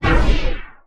MAGIC SPELL Short Fast Burst Quick Fade (stereo).wav